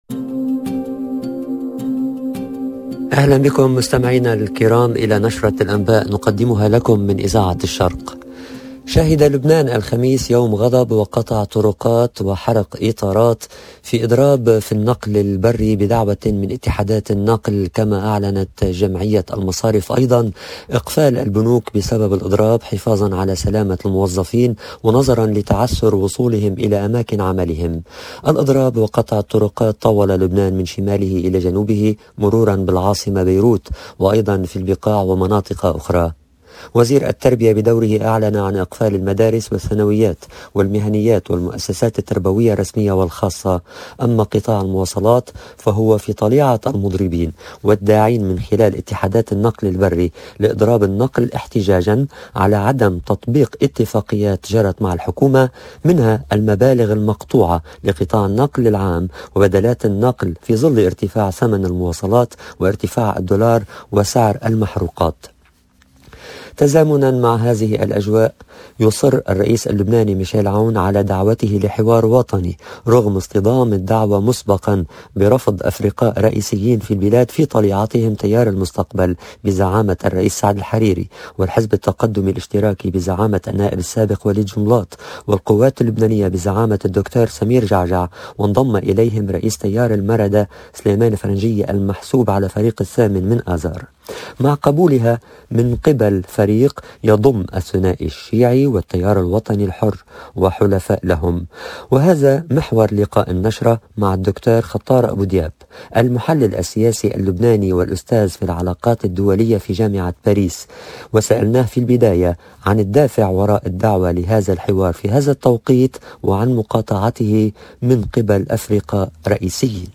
LE JOURNAL DU SOIR EN LANGUE ARABE DU 13/01/22